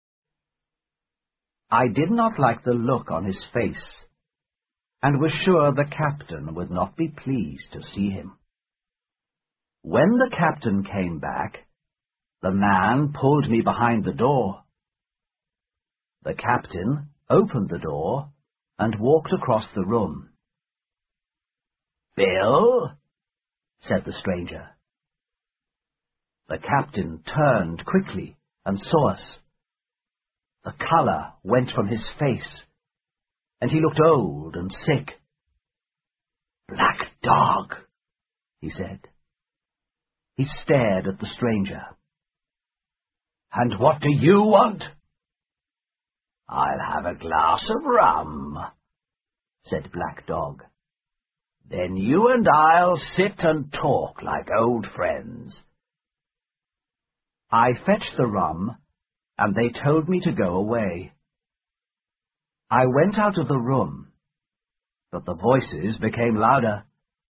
在线英语听力室《金银岛》的听力文件下载,《金银岛》中英双语有声读物附MP3下载